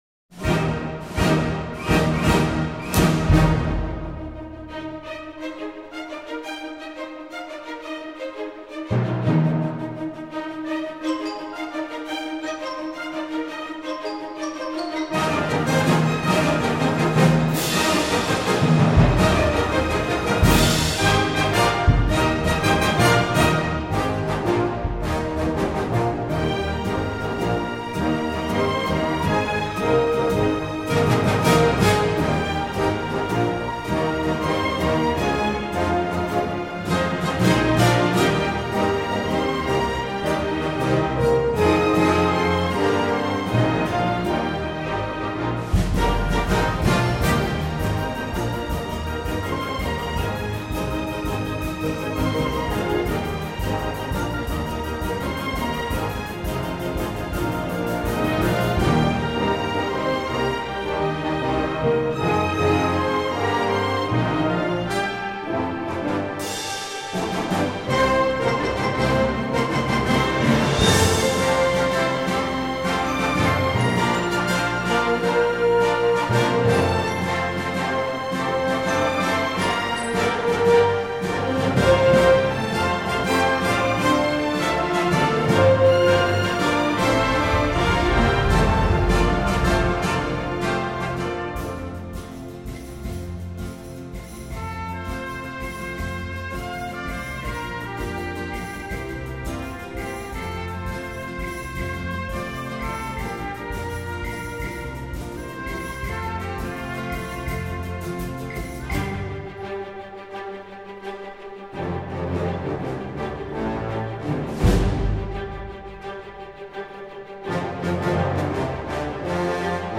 音乐中那激昂的斗志，凛然的正气，使人振奋鼓舞，不断焕发激情和力量。